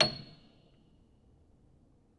Upright Piano